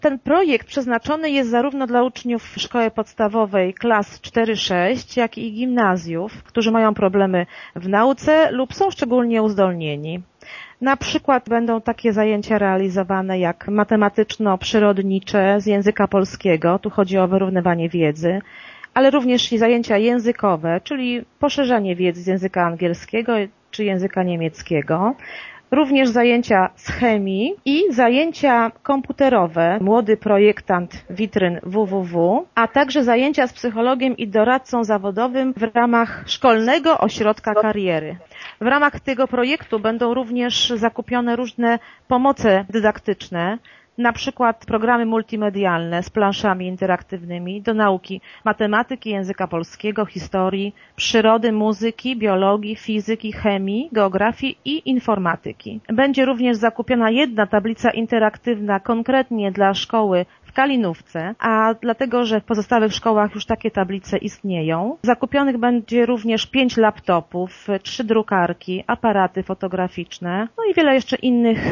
„Za te pieniądze zostanie zorganizowanych łącznie ponad 1200 godzin dodatkowych zajęć” – informuje zastępca wójta Urszula Paździor: